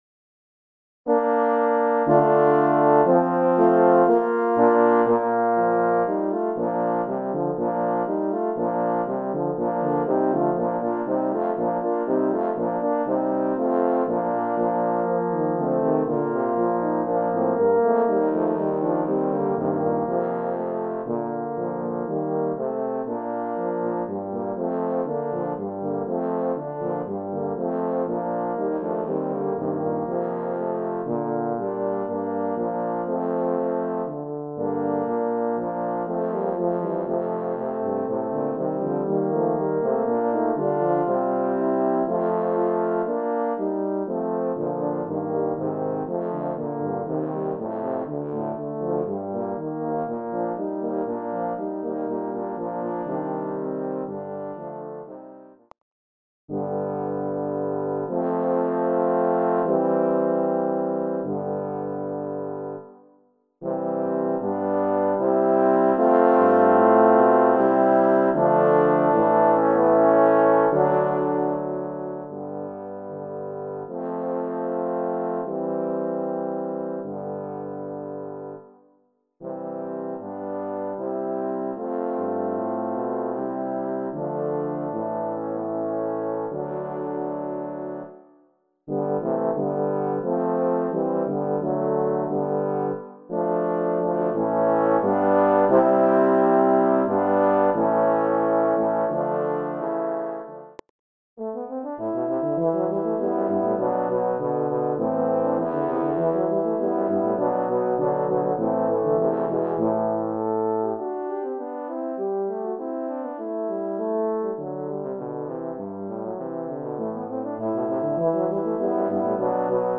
Voicing: Horn Quartet